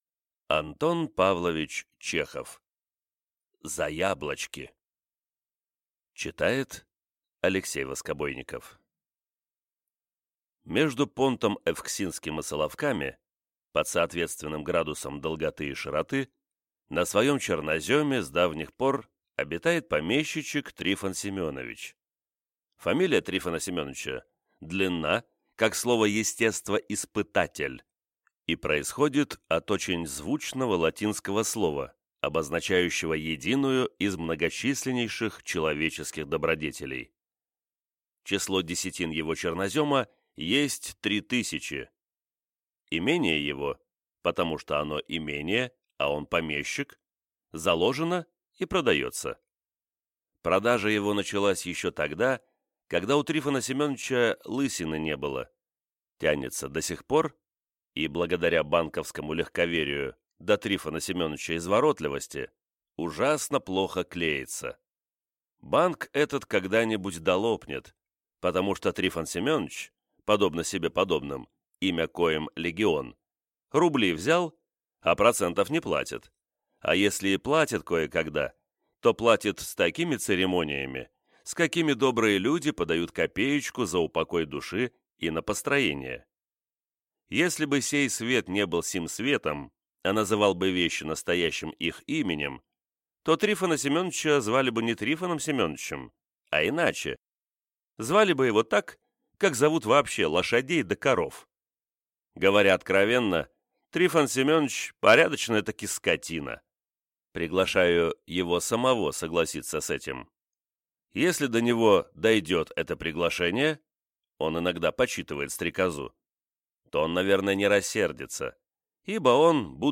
Аудиокнига За яблочки | Библиотека аудиокниг